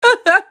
Weird laugh 23
laugh_lvh1lqy-mp3cut.mp3